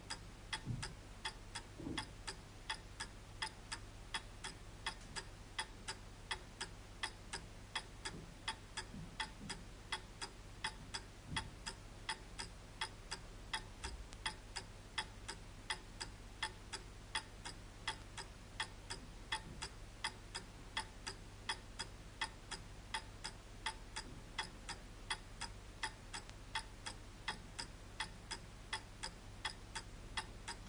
描述：索尼话筒的比较。在1.5米的距离上，分三段录制时钟的滴答声。前30秒是用ECMMS907对准时钟录制的。中间部分使用的是ECMMS957，也是指向时钟的。第三部分也是使用MS957，但话筒指向天花板，侧边的胶囊旋转旋钮被设置为与话筒的线路成直角。这最后一个设置似乎产生了最好的结果。在iRiver H120上录音，增益为12dB，使用MZN10的高灵敏度设置作为话筒前置放大器。30dB的增益是在后期处理中应用的。有多少噪音来自MZN10，我不知道。
标签： 时钟 麦克风 麦克风 噪声 壁虱 测试
声道立体声